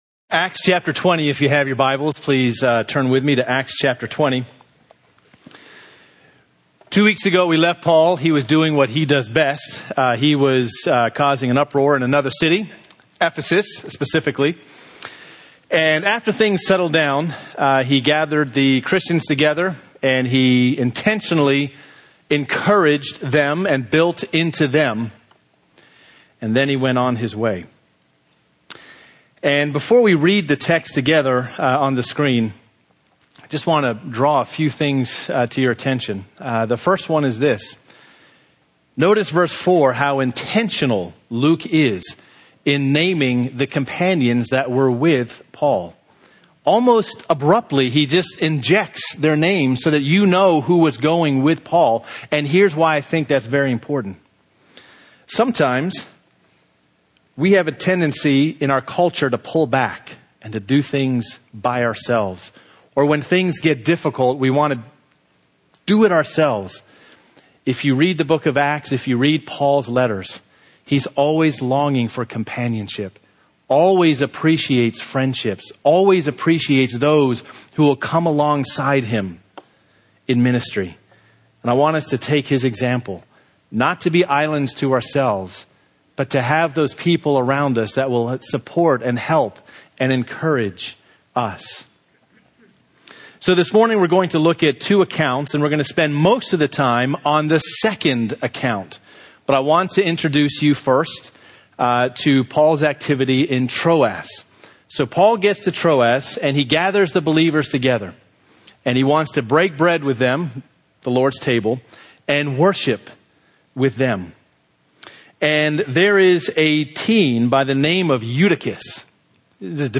Sermons (audio) — Derwood Bible Church